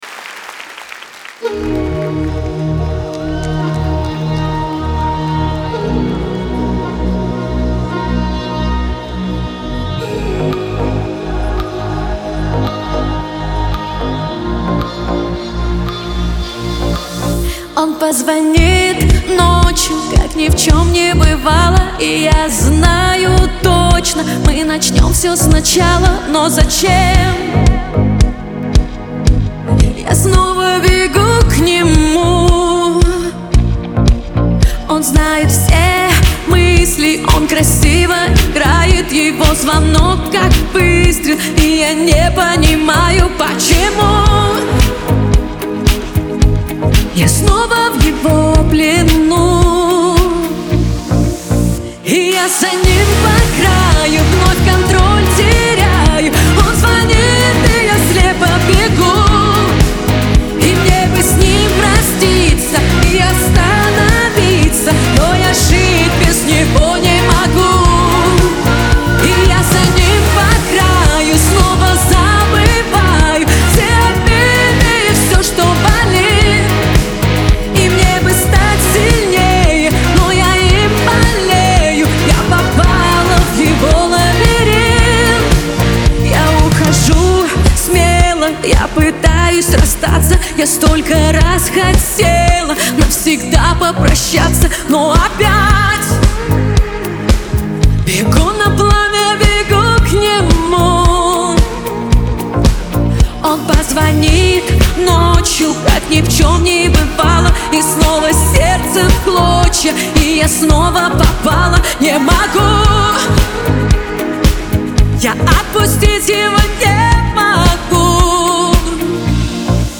Русские новинки